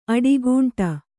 ♪ aḍigūṇṭa